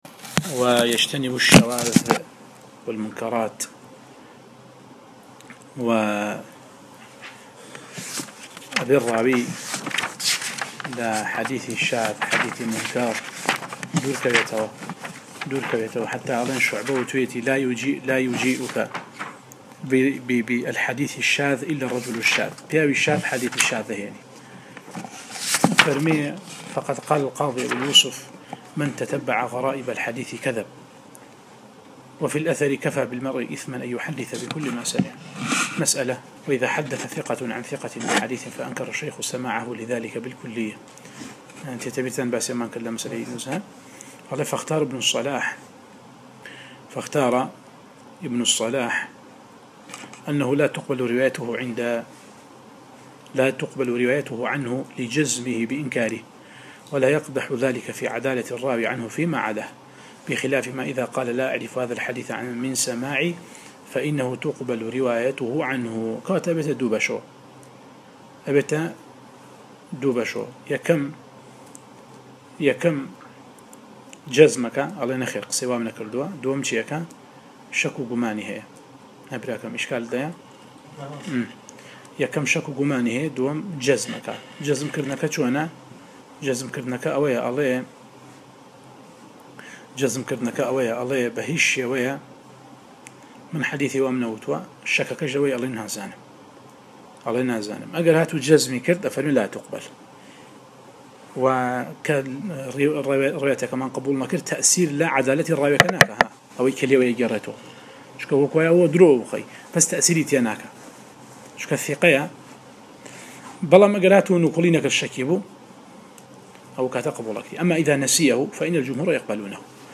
القراءة والتعليق على مواضع من الباعث الحثيث ـ 14